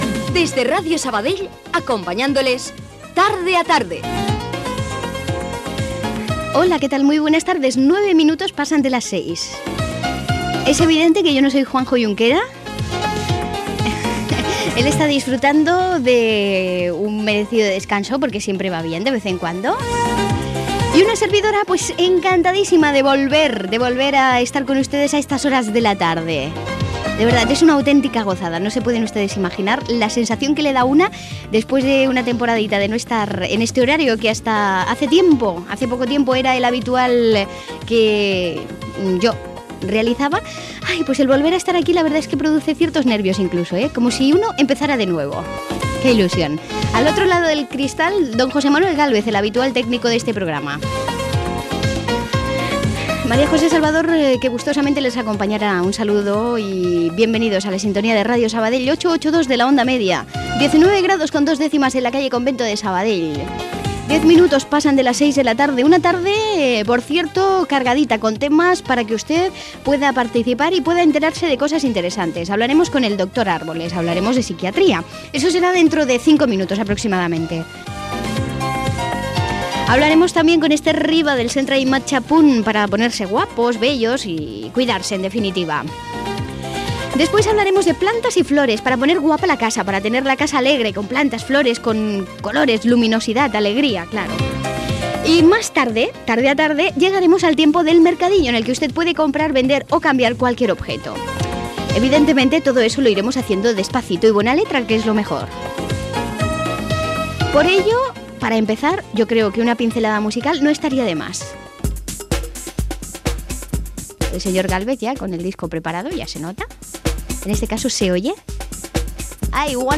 Careta del programa, presentació, temperatura, hora, sumari, hora i tema musical
Entreteniment